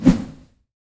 whoosh version 1